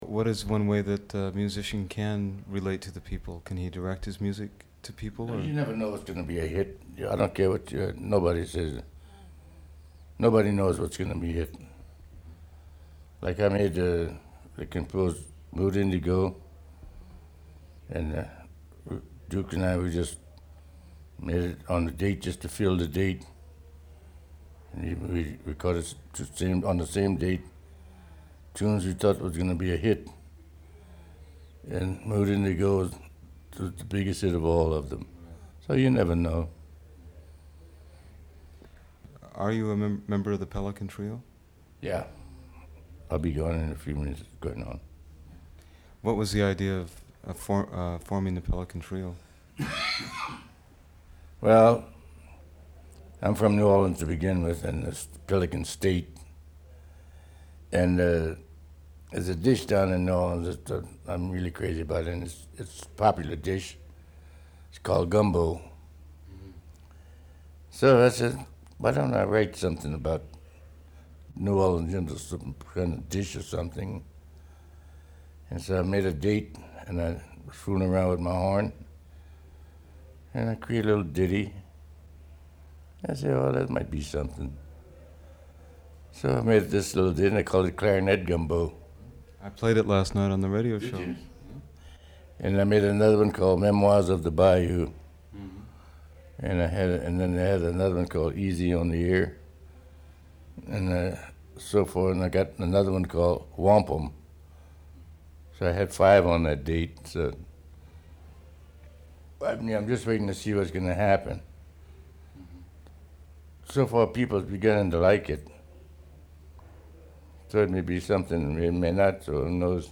Interviews | Project Jazz Verbatim